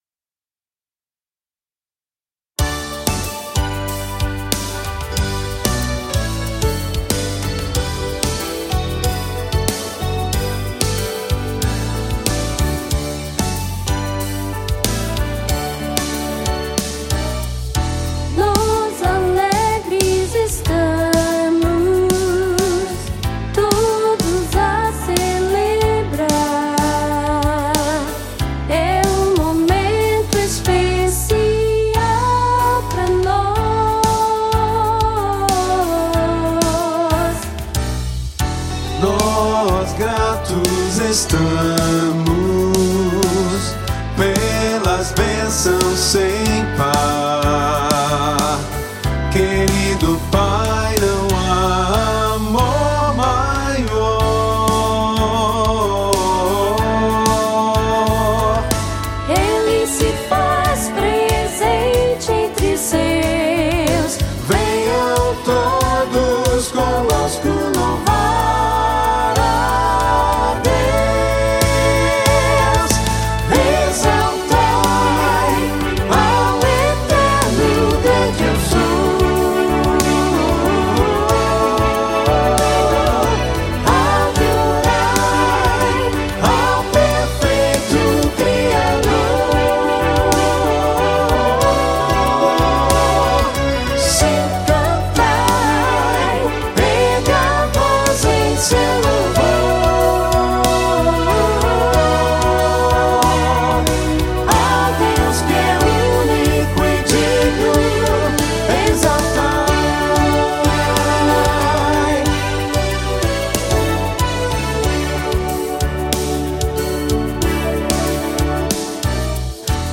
🎼 Orquestração
Flauta Transversa I e II
Oboé
Clarineta I e II
Trompa I e II
Trompete I e II
Trombone I e II
Tímpano
Violino I
Cello